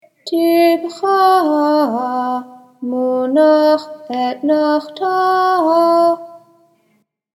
ETNACHTA PHRASES – HAFTARAH
haftarah-09-tipcha-munach-etnachta.mp3